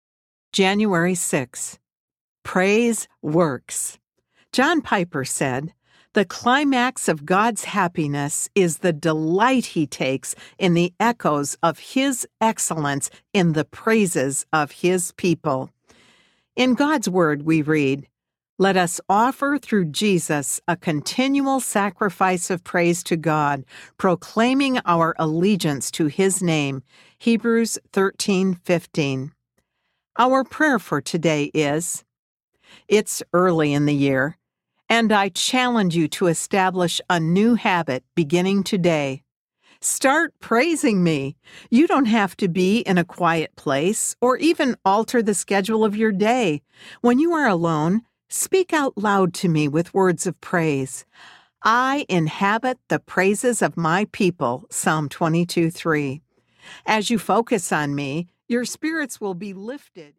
He Holds My Hand Audiobook